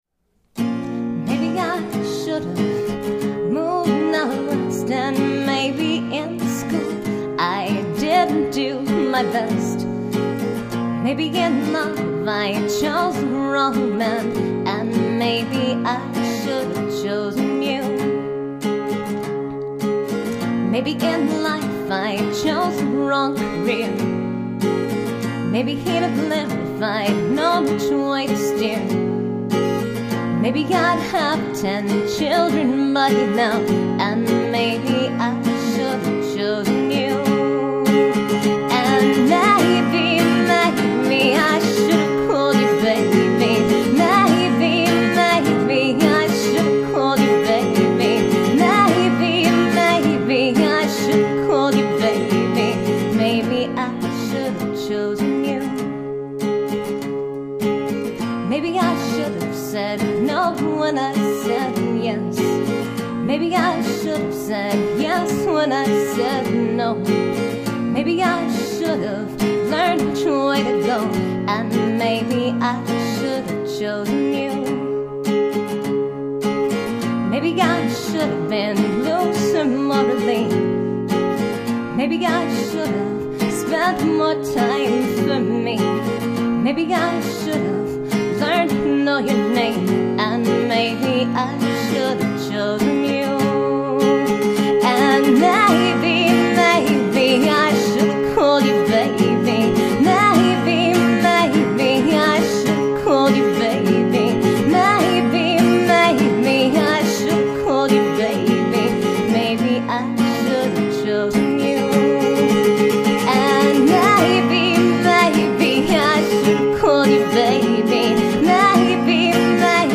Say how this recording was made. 64kbps mono MP3